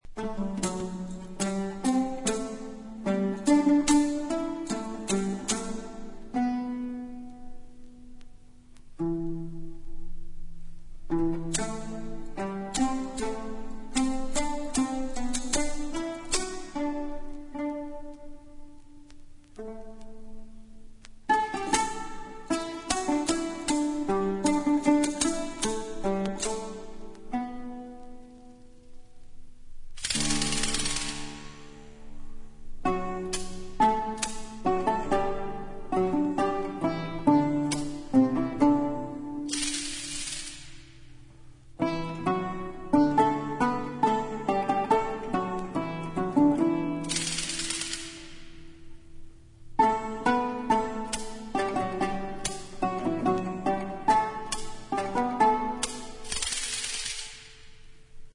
細部に至るまでをも表現するべく、当時の楽器36種類を復元・制作。